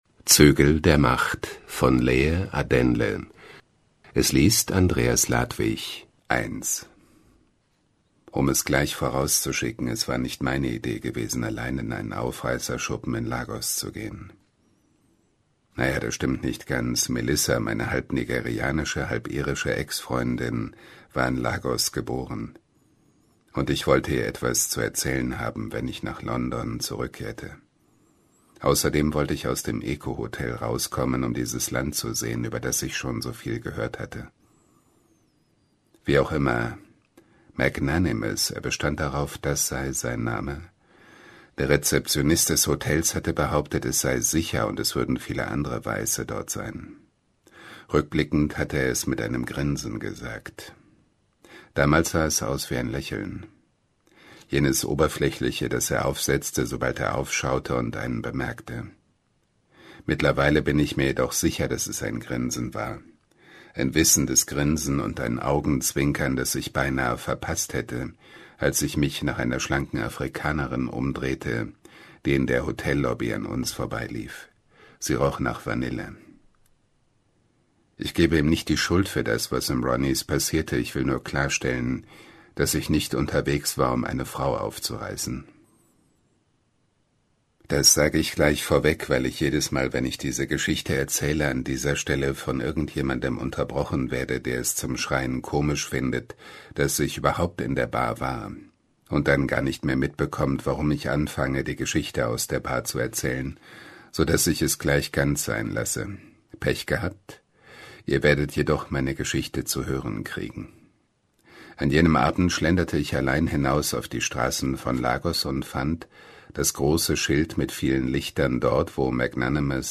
Diesen spannenden Thriller liest für Sie